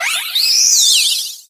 Cries
RIBOMBEE.ogg